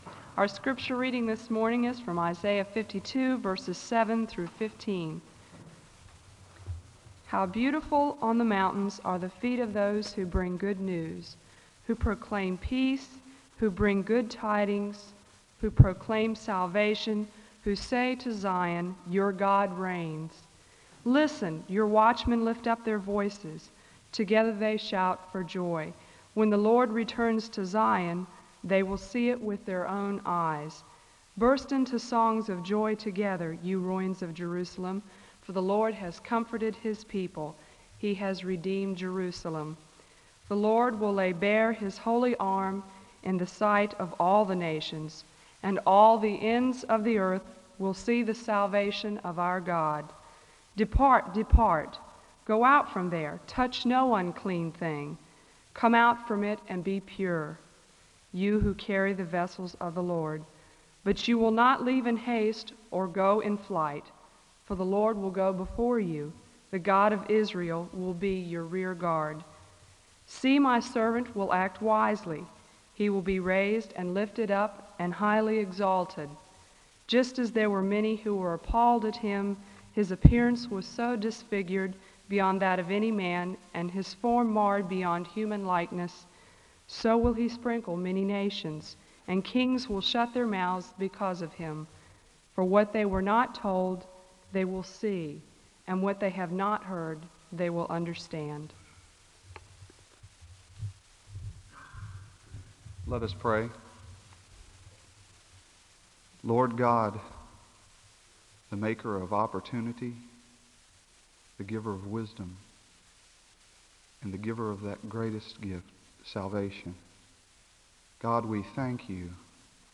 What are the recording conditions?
The service begins with a Scripture reading from Isaiah and a word of prayer (00:00-2:56). • Wake Forest (N.C.)